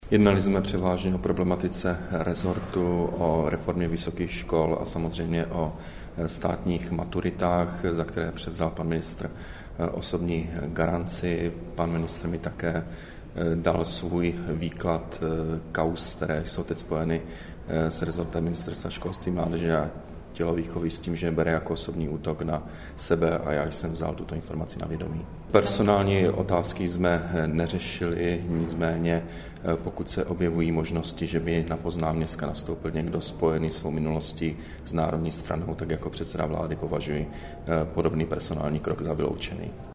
Premiérův komentář ke schůzce s ministrem Dobešem, 24. února 2011